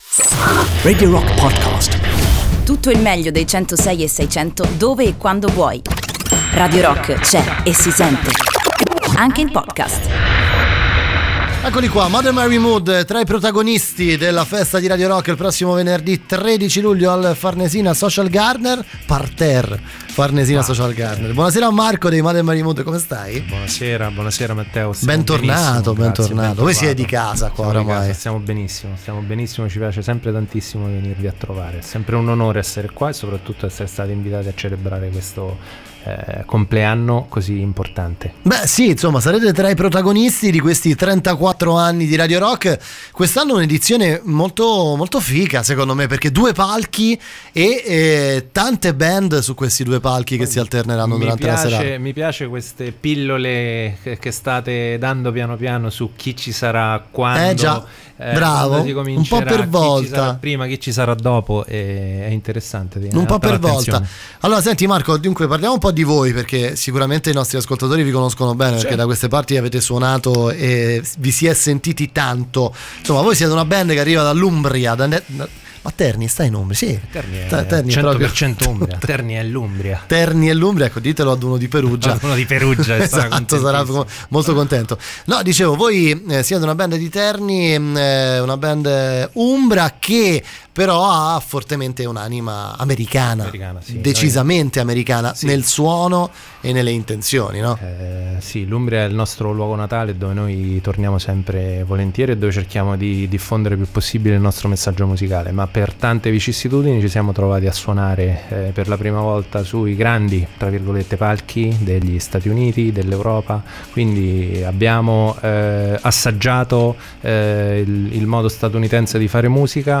Intervista: Mother Mary Mood (09-07-18)